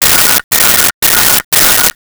Telephone Ring 03
Telephone Ring 03.wav